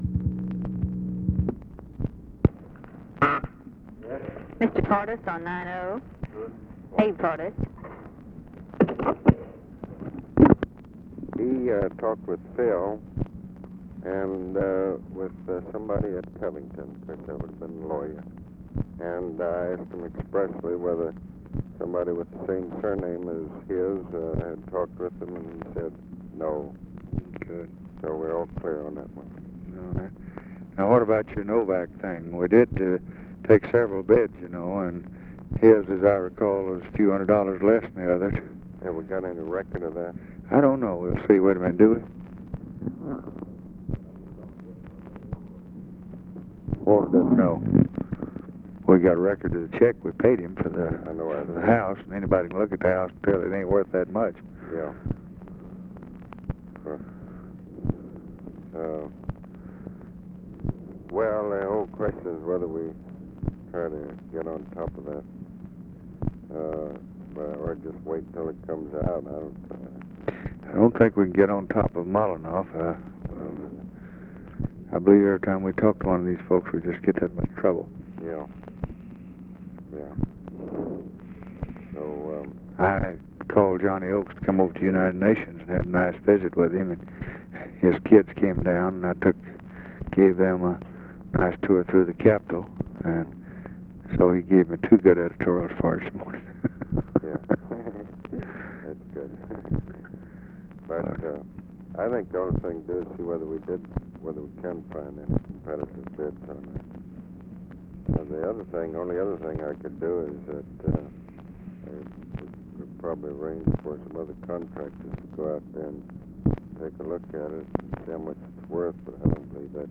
Conversation with ABE FORTAS and OFFICE CONVERSATION, January 24, 1964
Secret White House Tapes